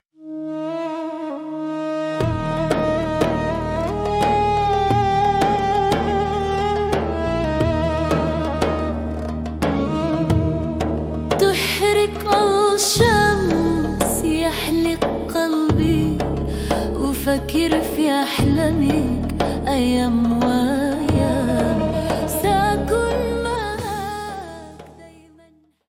Style : Oriental